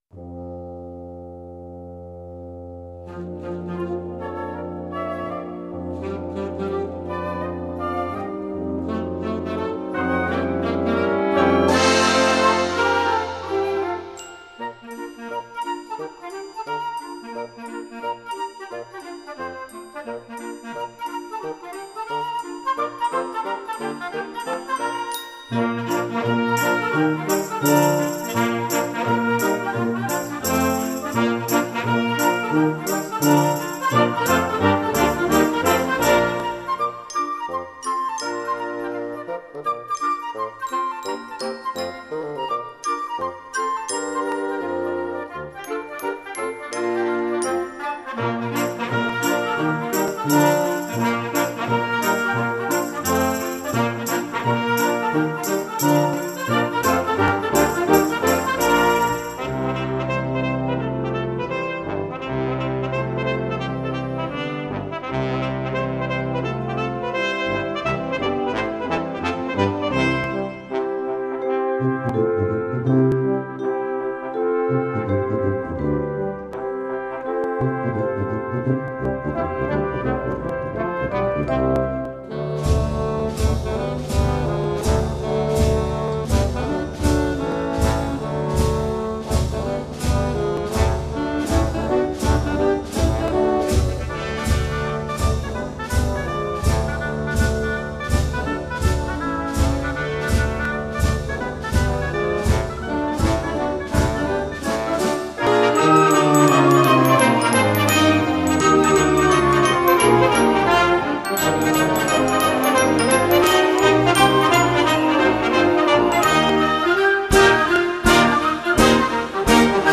Genre: Band
Percussion 1* (glockenspiel, vibes (opt.)